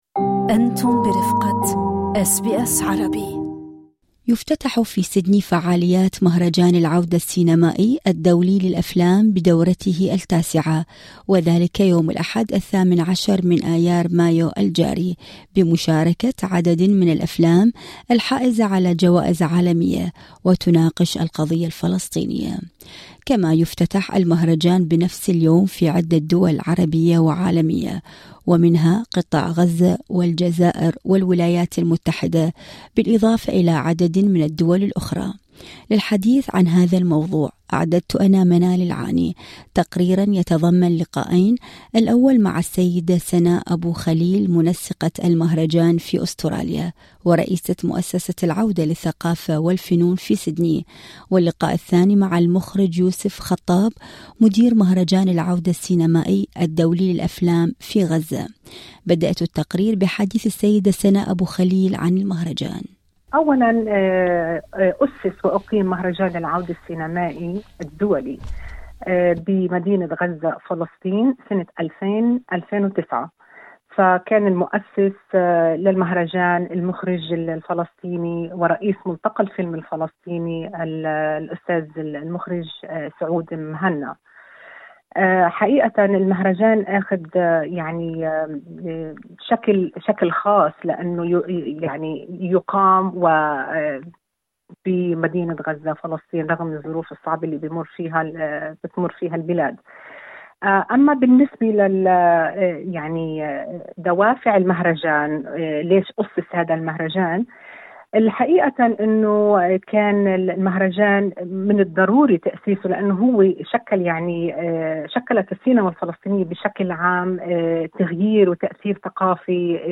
تقريراً يتضمن لقاءين